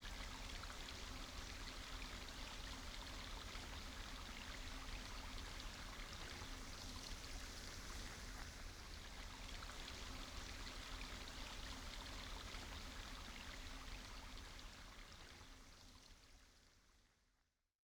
Water - Full Recording The full recording is 17 seconds in length This recording consists of the gentle sound of running water coming from the Caledonian Canal. The recording has a calming and soothing effect. There is some movement within the stereo image Raw audio, no processing Recorded 2010 using binaural microphones
Water, Full Recording.wav